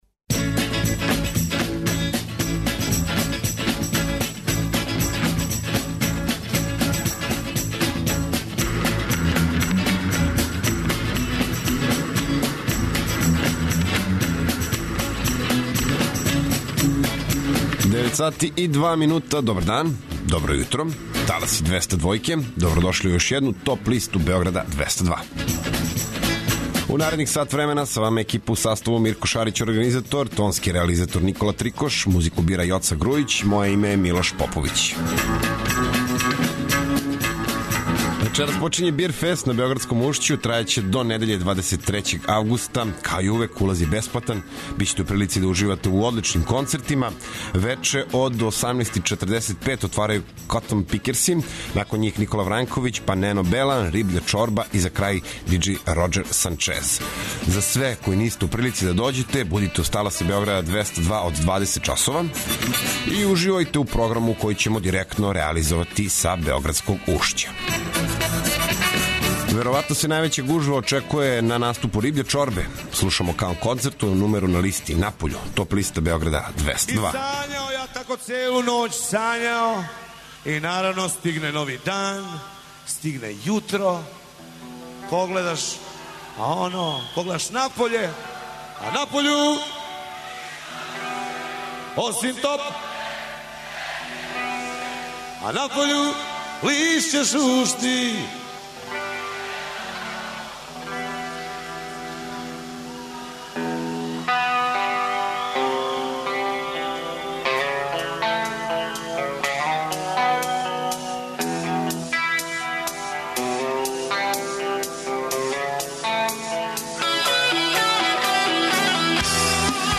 Најавићемо актуелне концерте у овом месецу, подсетићемо се шта се битно десило у историји рок музике у периоду од 17. до 21. августа. Ту су и неизбежне подлисте лектире, обрада, домаћег и страног рока, филмске и инструменталне музике, попа, етно музике, блуза и џеза, као и класичне музике.